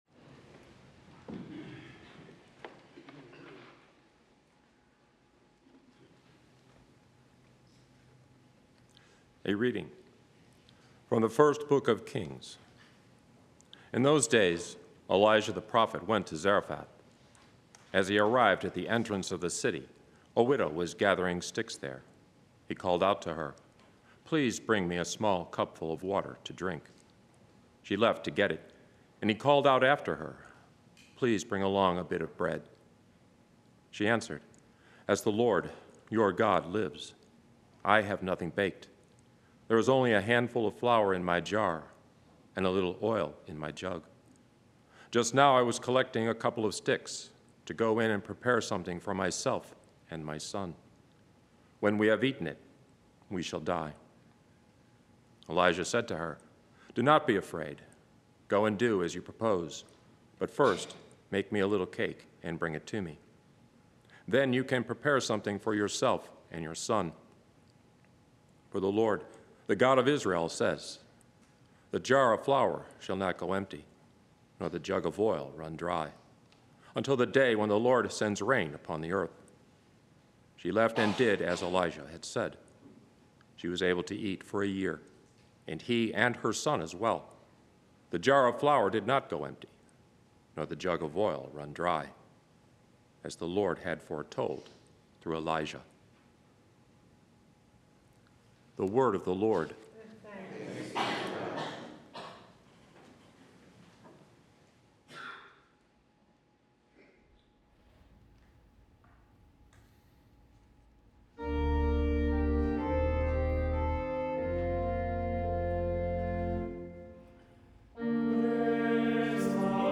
Readings, Homily and Daily Mass
From Our Lady of the Angels Chapel on the EWTN campus in Irondale, Alabama.